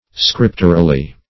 scripturally - definition of scripturally - synonyms, pronunciation, spelling from Free Dictionary Search Result for " scripturally" : The Collaborative International Dictionary of English v.0.48: Scripturally \Scrip"tur*al*ly\, adv.
scripturally.mp3